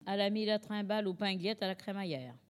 Collectif-Patois (atlas linguistique n°52)
Catégorie Locution